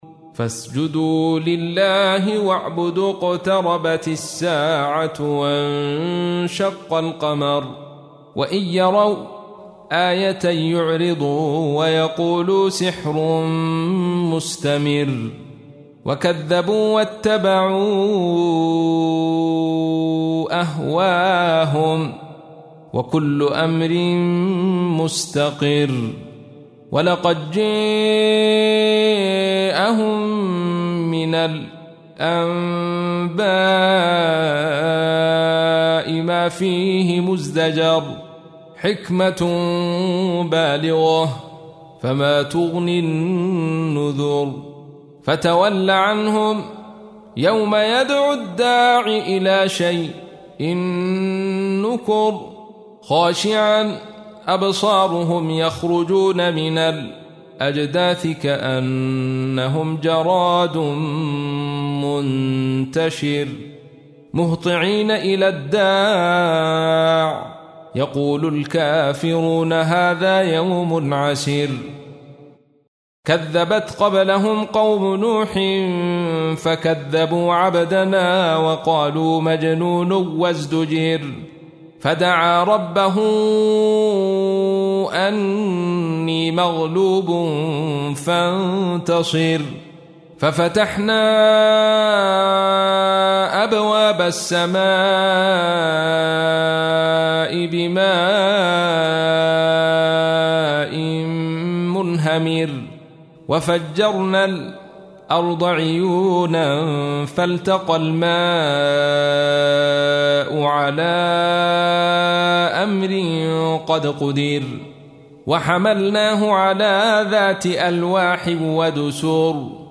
تحميل : 54. سورة القمر / القارئ عبد الرشيد صوفي / القرآن الكريم / موقع يا حسين